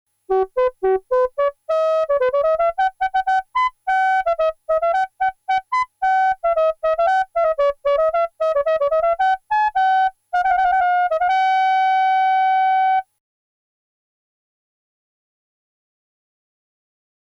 Tous ces sons ont été enregistrées directement en sortie du DX7, donc sans aucun effet : ni reverb, ni chorus.
VivaldiAC p.173 : trompette classique. Très difficile à réussir : le médium est assez bien, mais la FM doit pouvoir reproduire la brillance du cuivre. Objectif non atteint ici.